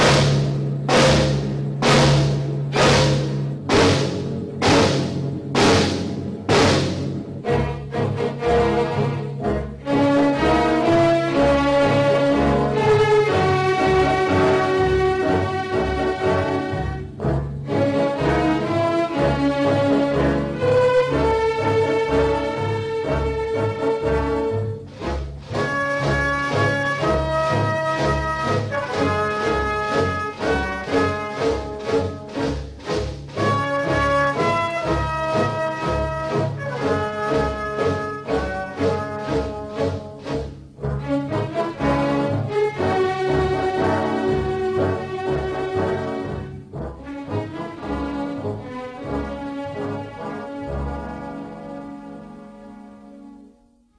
Musica:
Original Track Music (1.00)